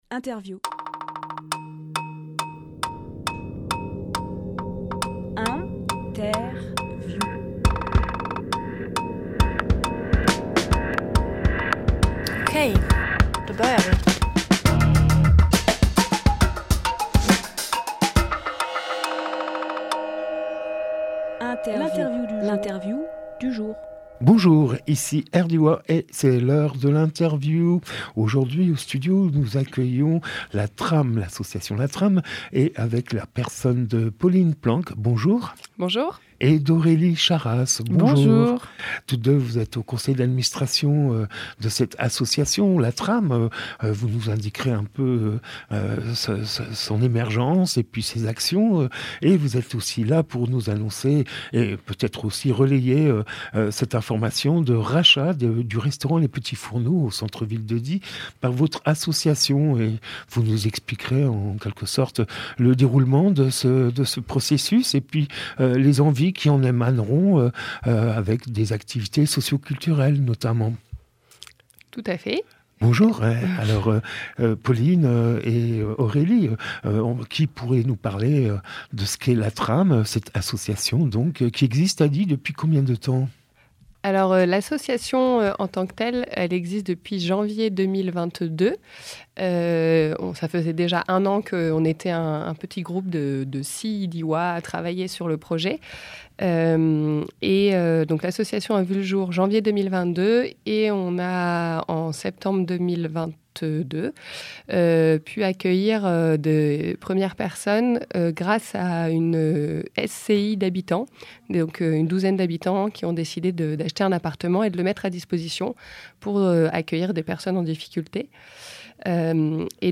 À LA RADIO !